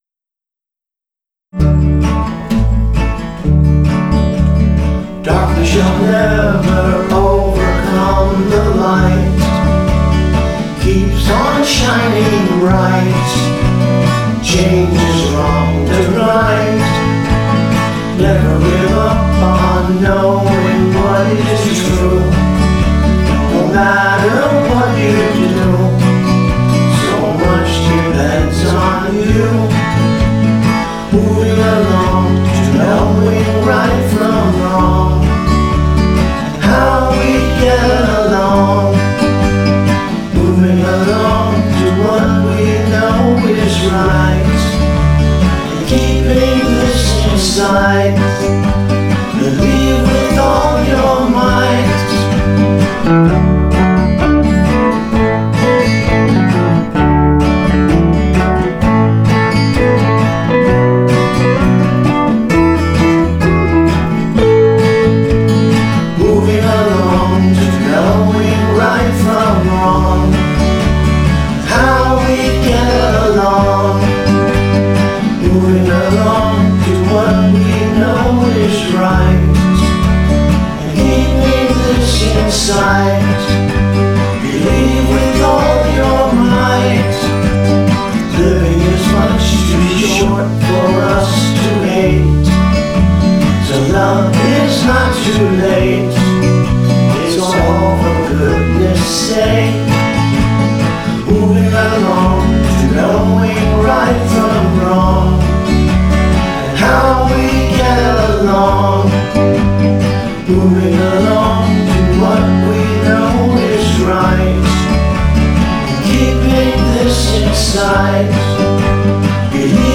I am playing all instruments and singing all vocals.